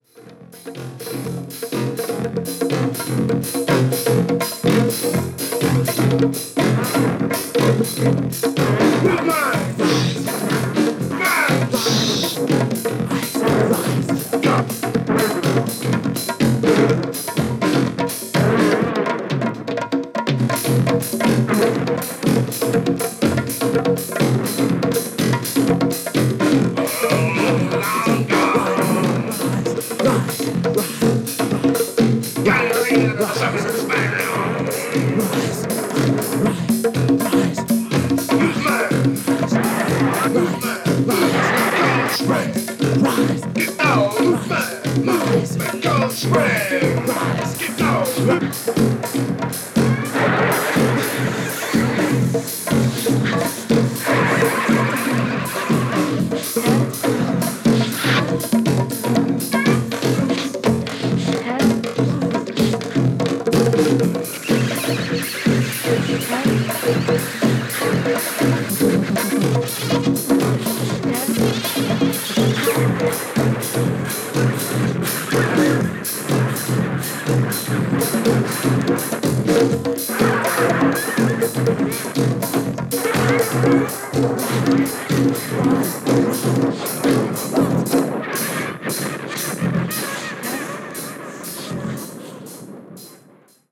実験的な勢いがステキですね！！！